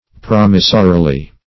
promissorily - definition of promissorily - synonyms, pronunciation, spelling from Free Dictionary Search Result for " promissorily" : The Collaborative International Dictionary of English v.0.48: Promissorily \Prom"is*so*ri*ly\, adv.
promissorily.mp3